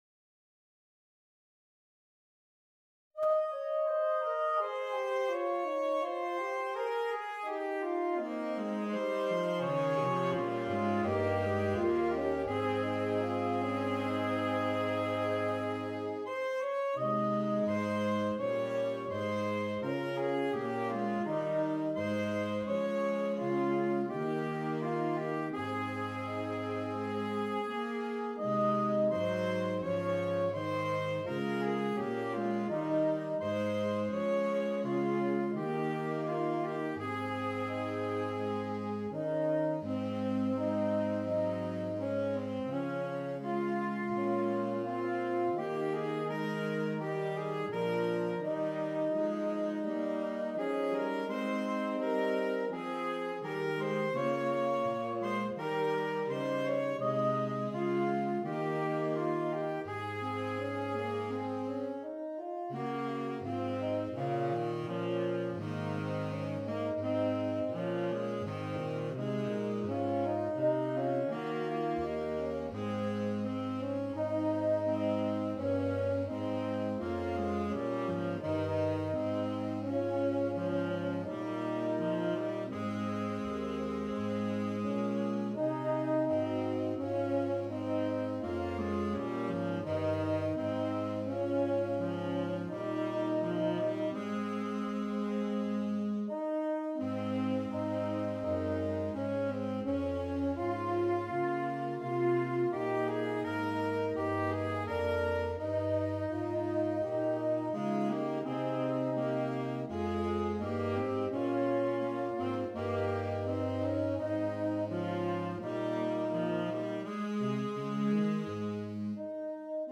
Saxophone Quartet (AATB)
Traditional